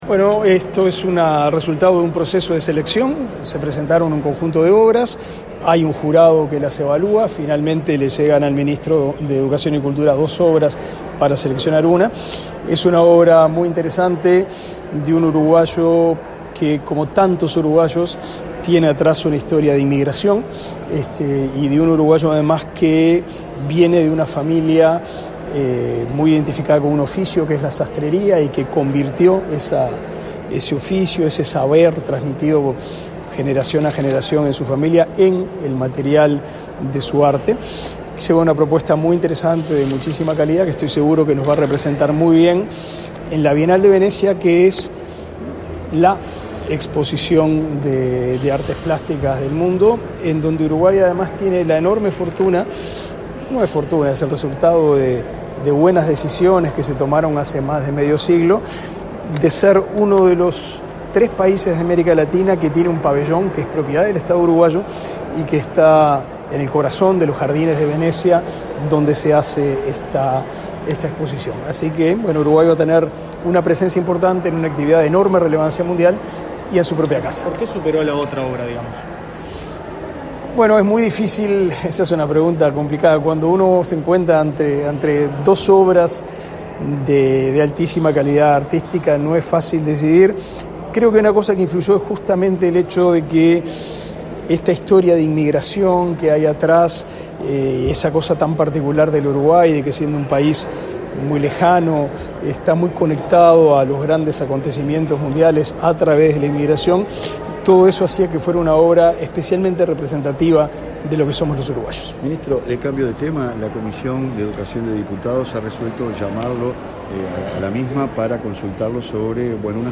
Declaraciones a la prensa del ministro Pablo da Silveira
Declaraciones a la prensa del ministro Pablo da Silveira 18/03/2022 Compartir Facebook X Copiar enlace WhatsApp LinkedIn El ministro de Educación y Cultura, Pablo da Silveira, participó de una conferencia de prensa sobre la participación de Uruguay en la Bienal de Venezia y, luego, dialogó con la prensa.